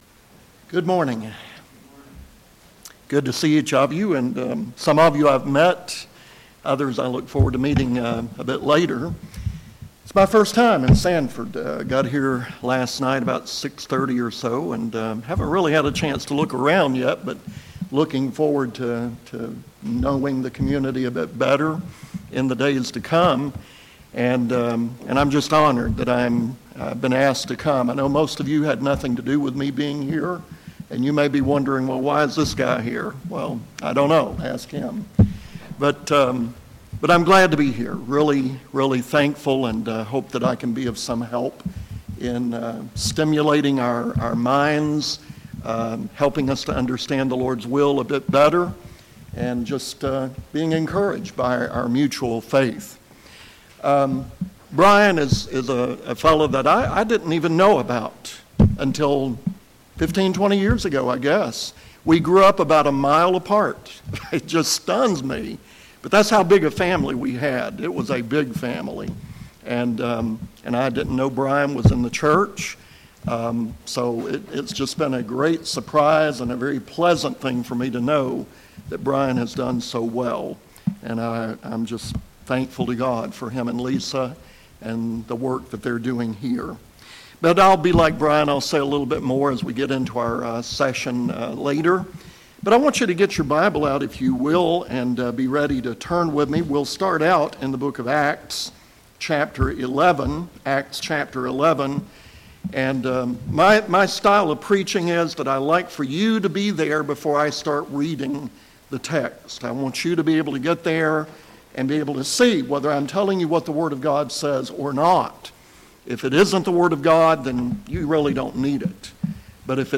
Bible Class: A Grace-filled Church
Service Type: Gospel Meeting